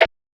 TS Perc_2.wav